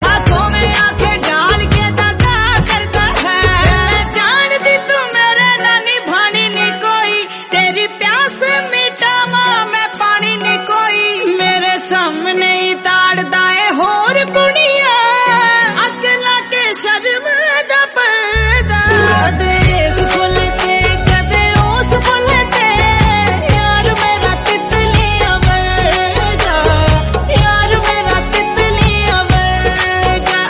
Category Bollywood